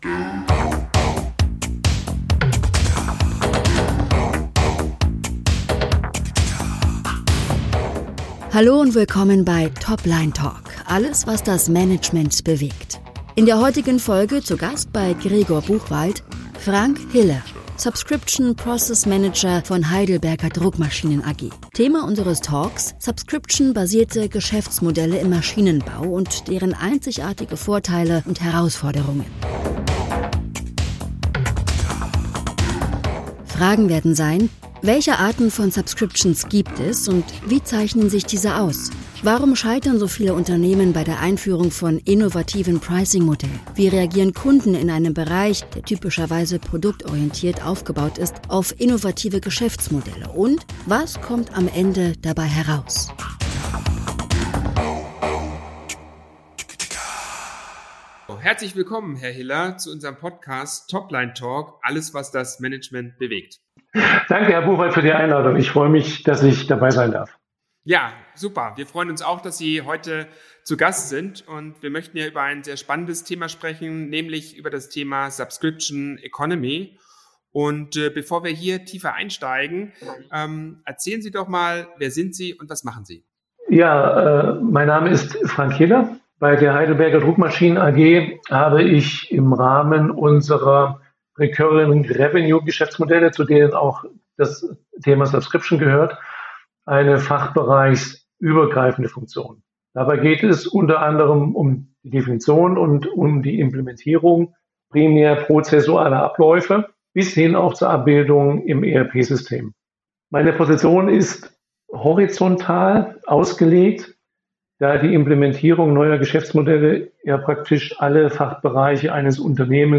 Heute im Talk: Subscription-basierte Geschäftsmodelle im Maschinenbau und deren einzigartige Vorteile und Herausforderungen. Fragen werden sein: Welche Arten von Subscriptions gibt es und wie zeichnen sich diese aus? Warum scheitern so viele Unternehmen bei der Einführung von innovativen Pricing-Modellen?